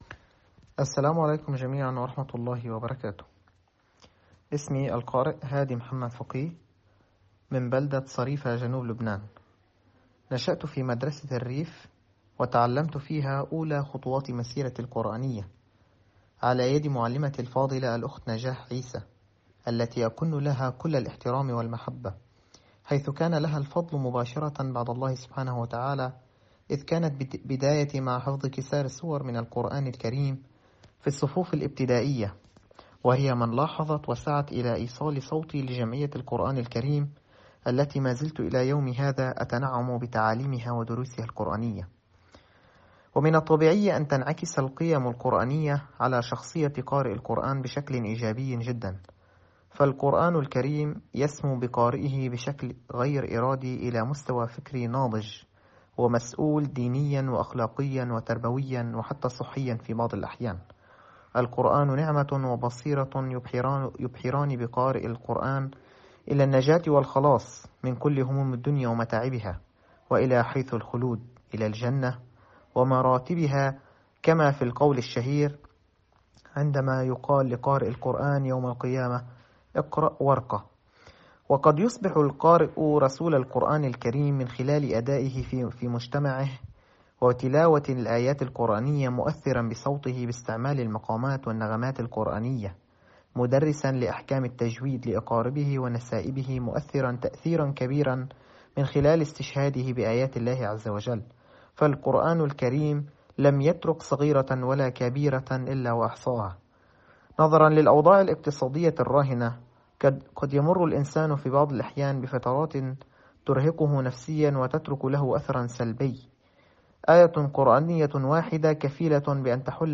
فيما يلي المقطع الصوتي الكامل لتلاوة القارئ اللبناني